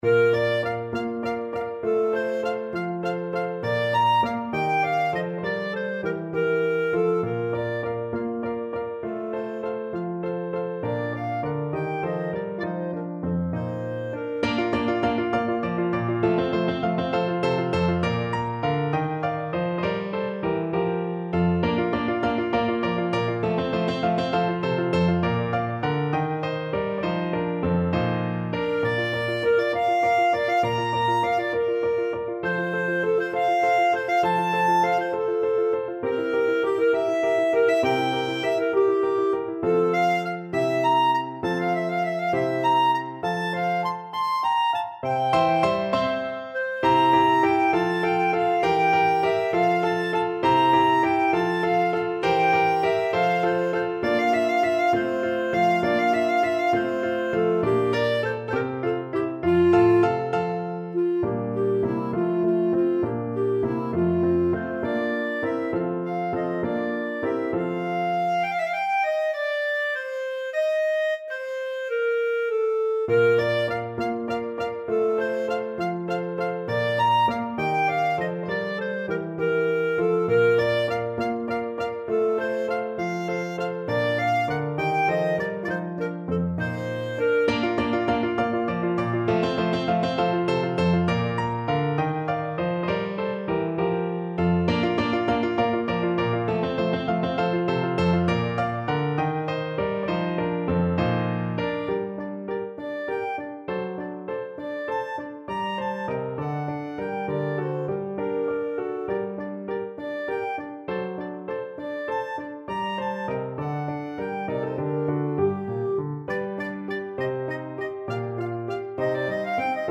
Rondo
6/8 (View more 6/8 Music)
Classical (View more Classical Clarinet Music)